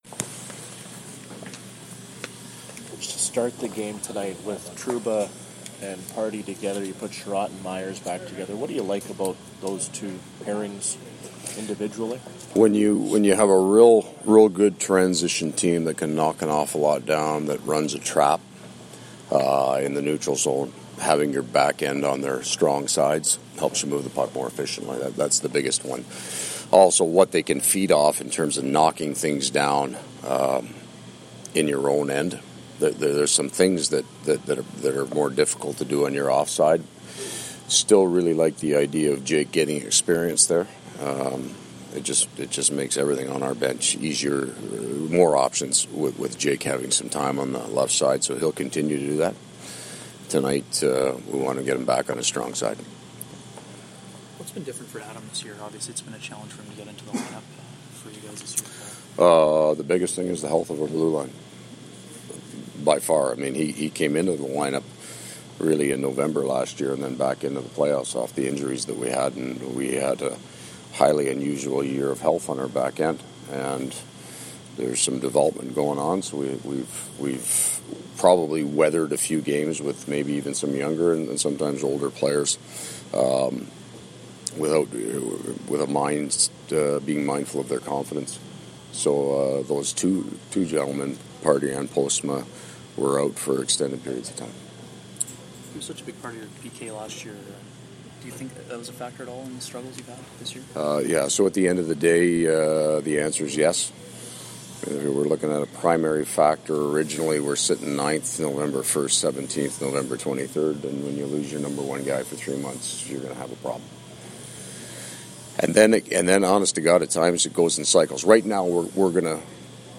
Winnipeg Jets coach Paul Maurice pre-game scrum (Stars)
Coach Maurice’s game day comments.
Coach Maurice in Dallas